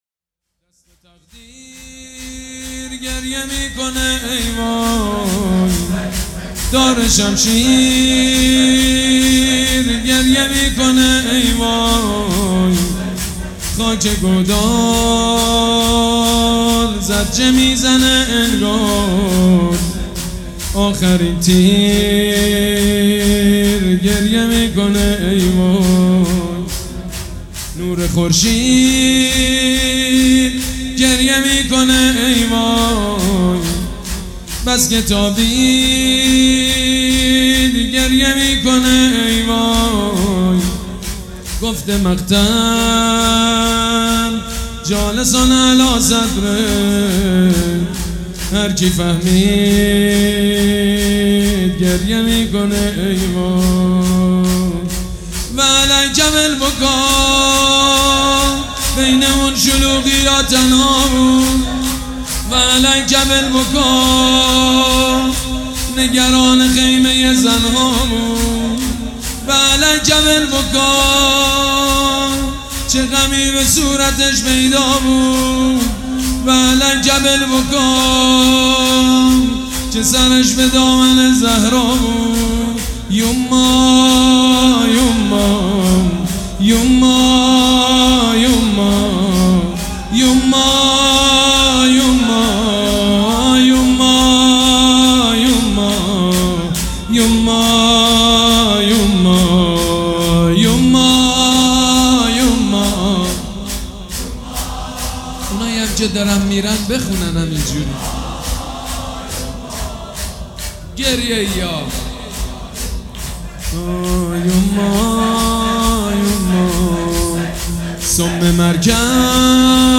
شور
مداح
مراسم عزاداری شب عاشورا